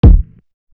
FLODGIN' KICK.wav